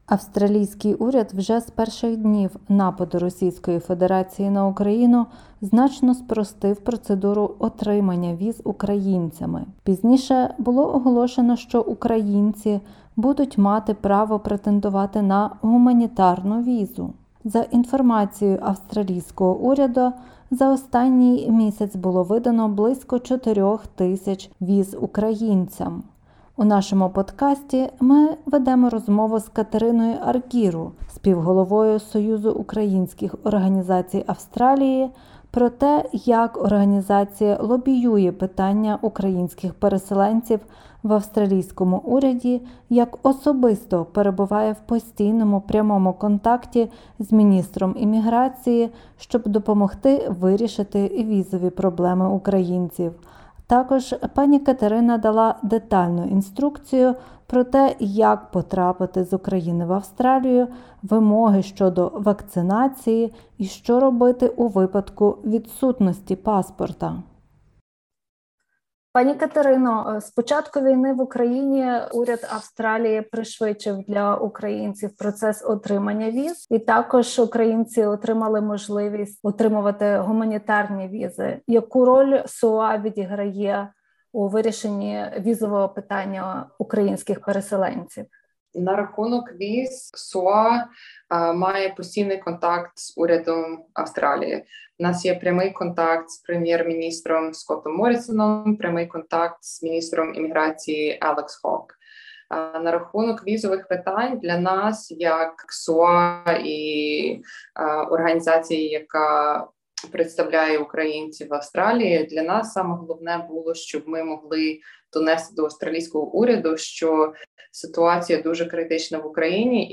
У нашому подкасті ми ведемо розмову